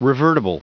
Prononciation du mot revertible en anglais (fichier audio)
Prononciation du mot : revertible